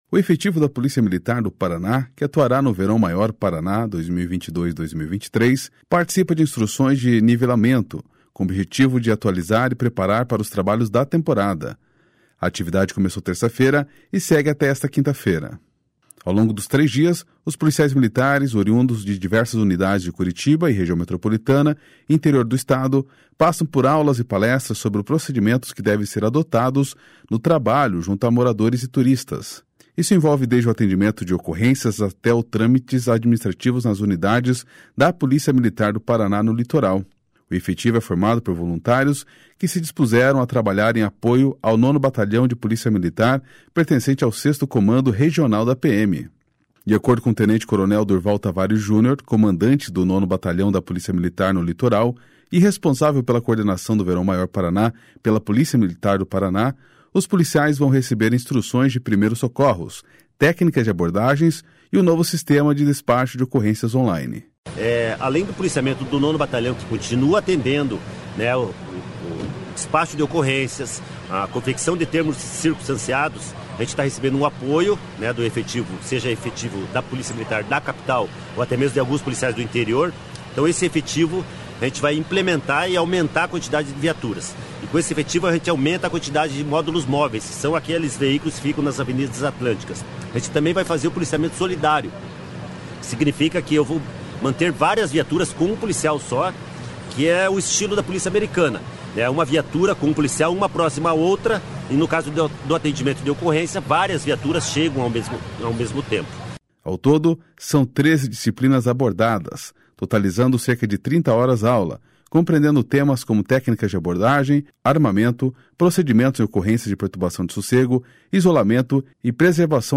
Ao todo, são 13 disciplinas abordadas, totalizando cerca de 30 horas-aula, compreendendo temas como técnicas de abordagem, armamento, procedimentos em ocorrências de perturbação do sossego, isolamento e preservação local de crime. (Repórter: